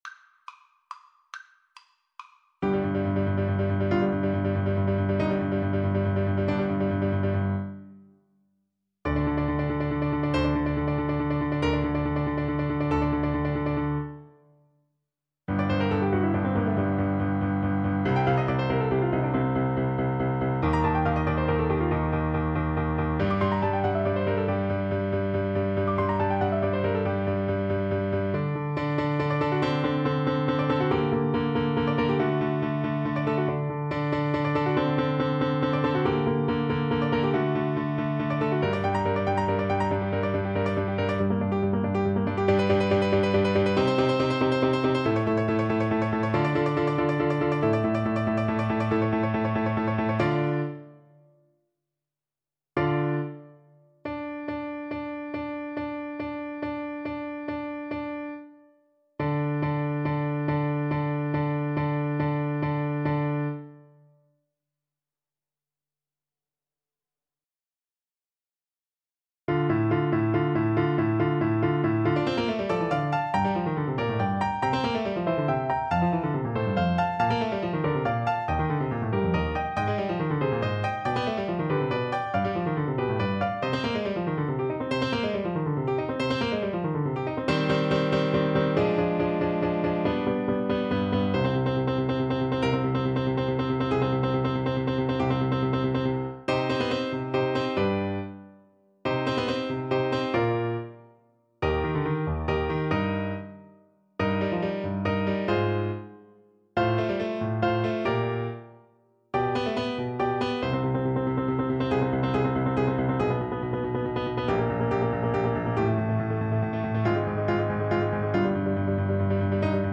~ = 140 Presto (View more music marked Presto)
3/4 (View more 3/4 Music)
Classical (View more Classical Clarinet Music)